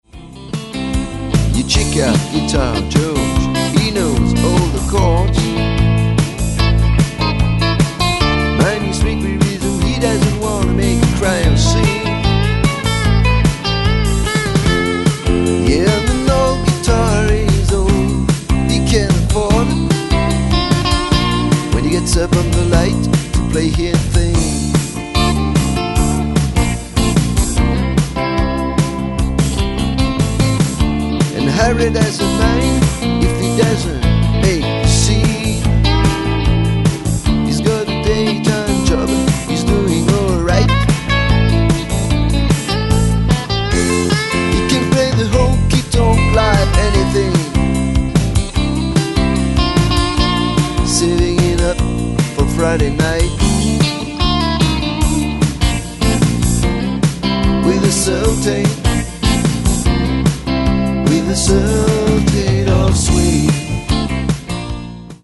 Basse, Chant, Harmonies
Banjo
Batterie
Guitare Electrique